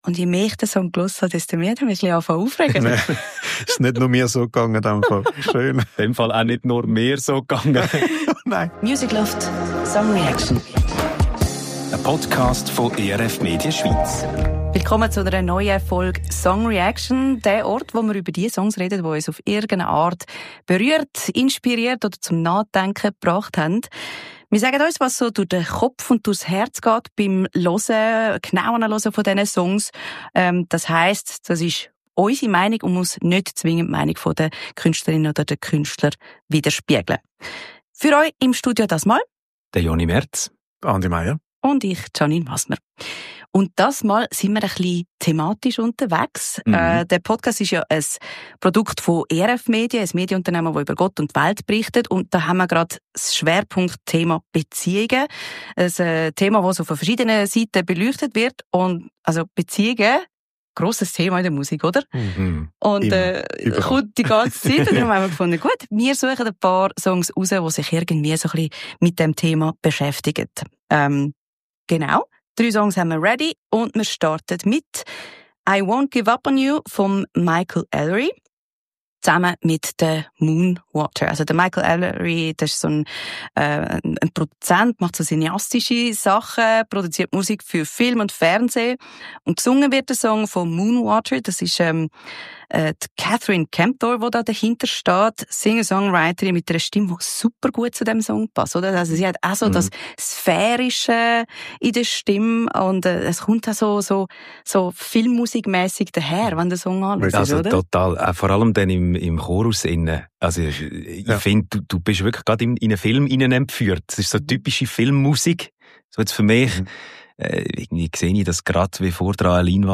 Zu dritt sprechen wir über die Wichtigkeit, füreinander da zu sein – in den kleinen und grossen Krisen des Lebens.